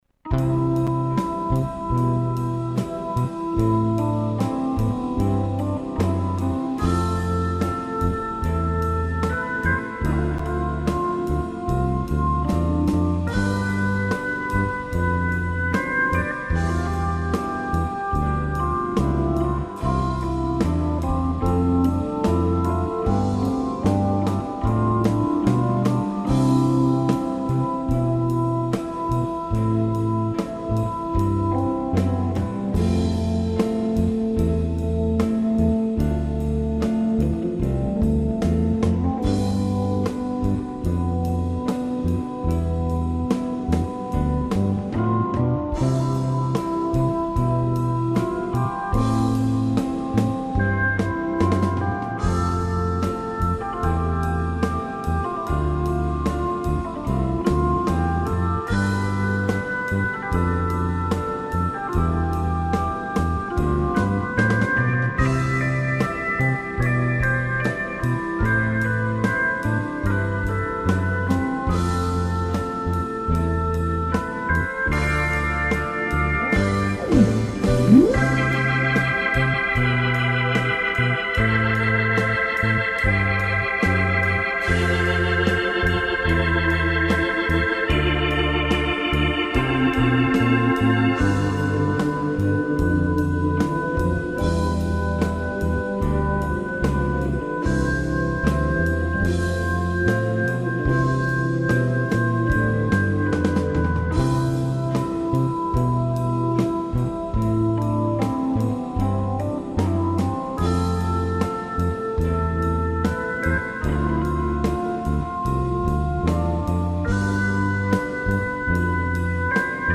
«Background» con solo parte de «Hammond»